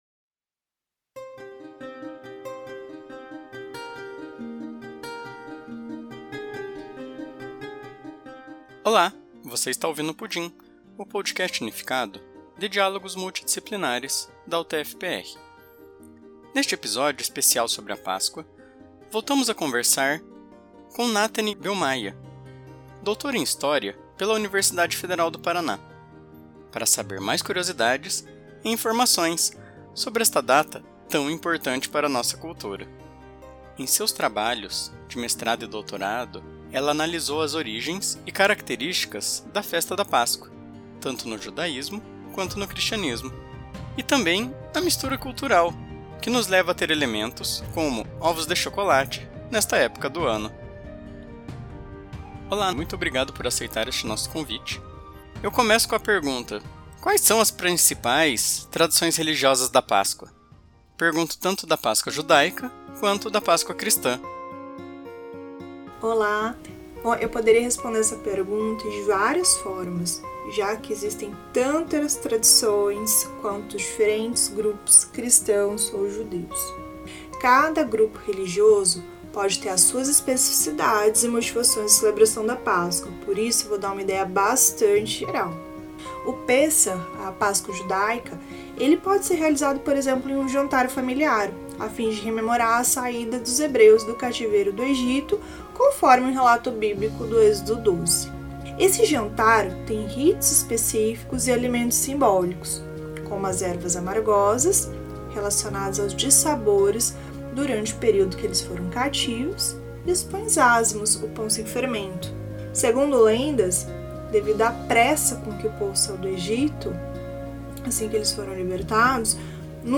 Quando e como surgiu a “ Tradição da Páscoa”? Neste bate-papo curto